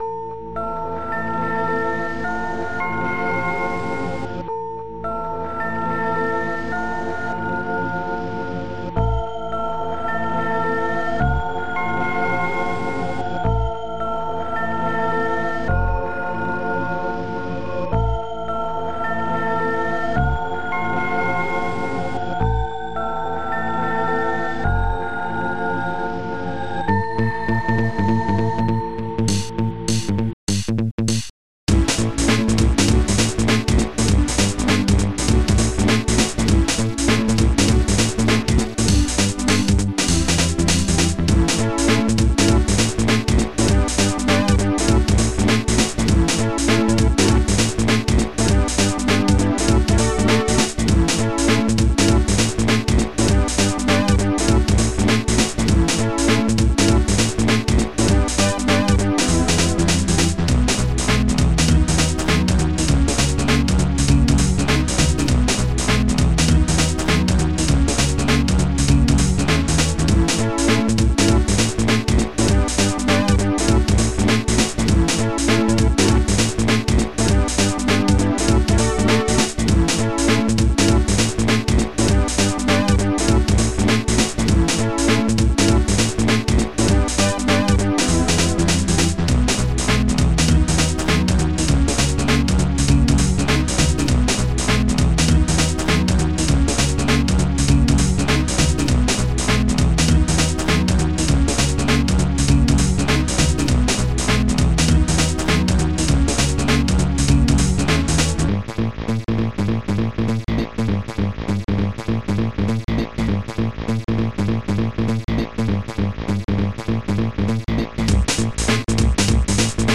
Protracker Module  |  2000-10-02  |  131KB  |  2 channels  |  44,100 sample rate  |  2 minutes, 36 seconds
Protracker and family
ST-25:staticU20BRASS1
ST-94:kkslapbass